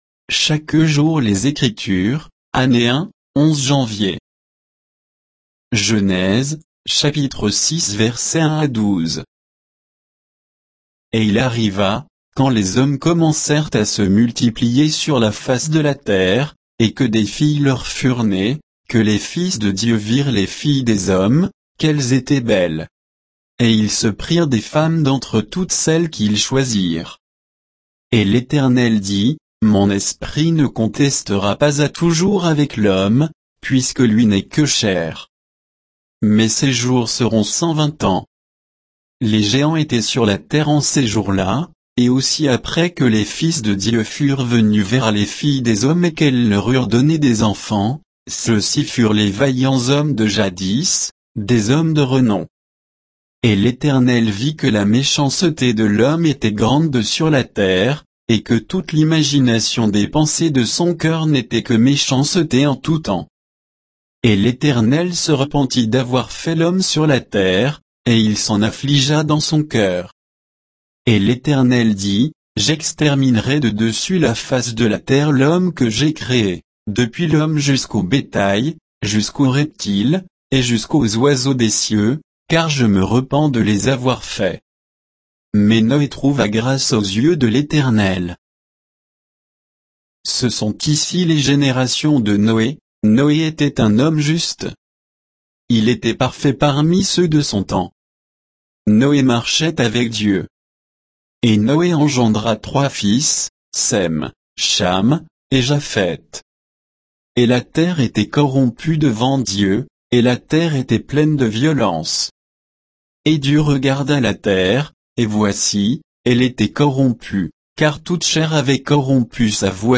Méditation quoditienne de Chaque jour les Écritures sur Genèse 6